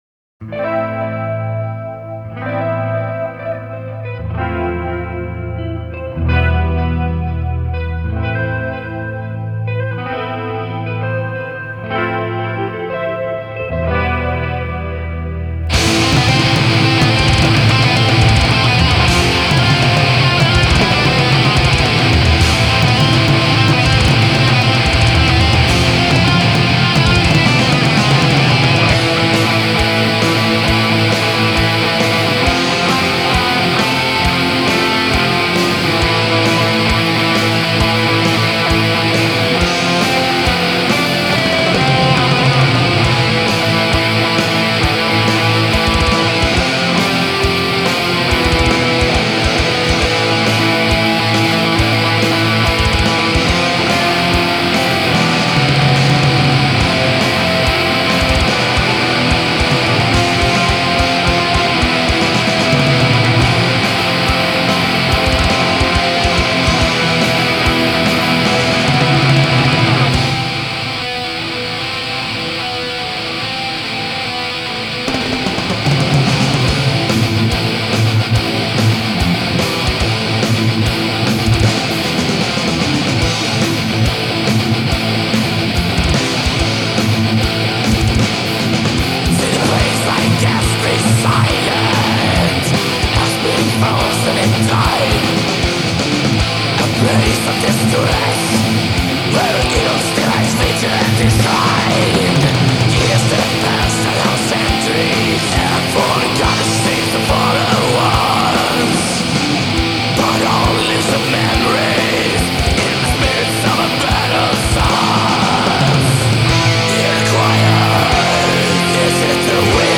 Dibuka dengan petikan gitar yang sungguh ganjil, dan bluesy.